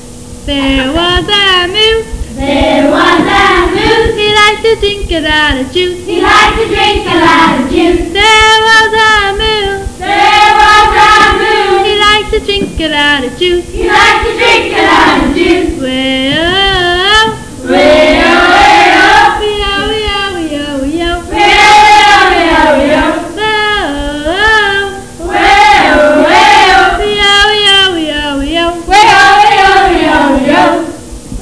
Click on any of the following songs to hear us singing: